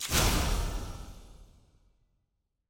sfx-eog-ui-challenger-burst.ogg